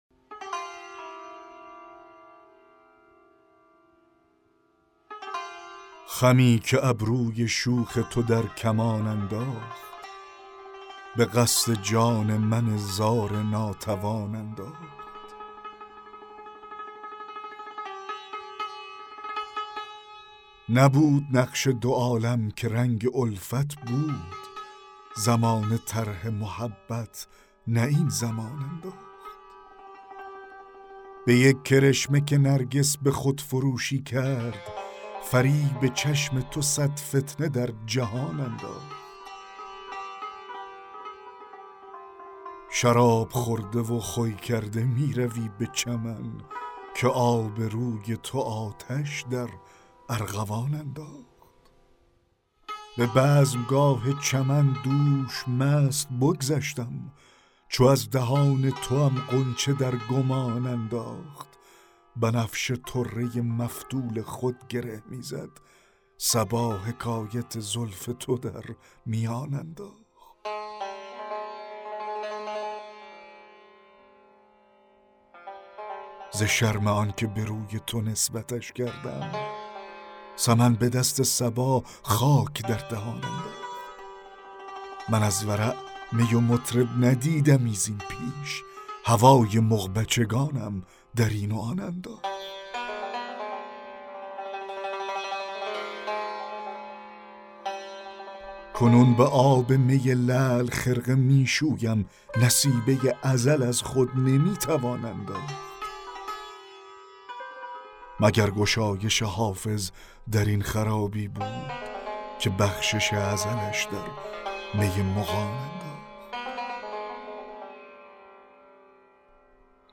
دکلمه غزل 16 حافظ
دکلمه-غزل-16-حافظ-خمی-که-ابروی-شوخ-تو-در-کمان-انداخت.mp3